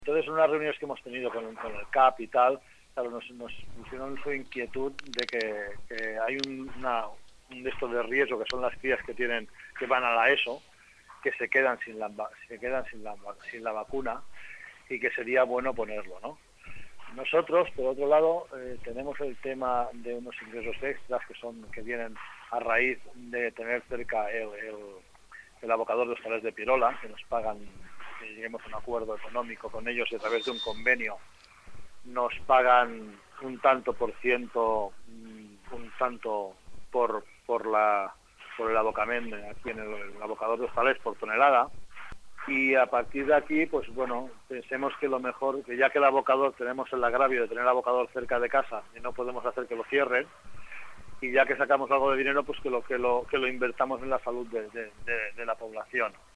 Enric Gómez, regidor de Salut de Masquefa, explica que el consistori vacunarà les nenes nascudes entre el 1993 i el 1996 i en pagarà la meitat